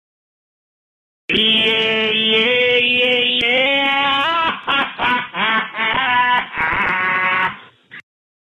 Yeeeeah Sing It Ghostie AutoTuned.mp3